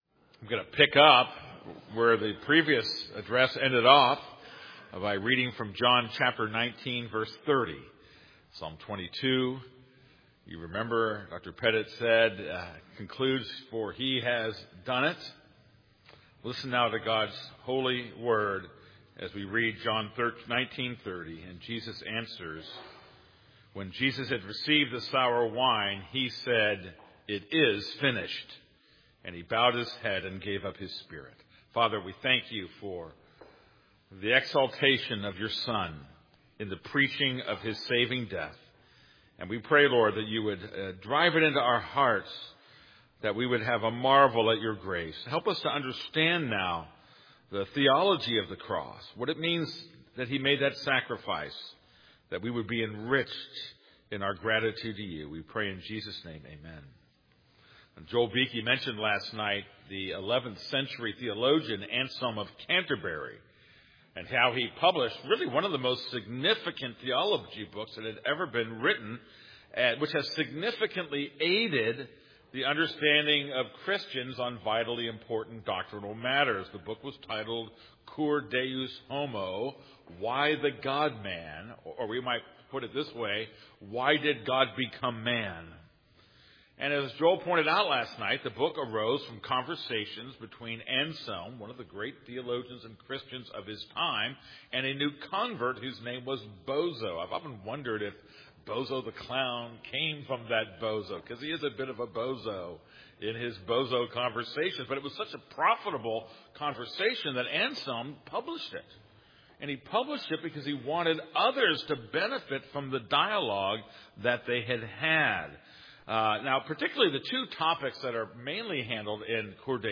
This is a sermon on John 19:30.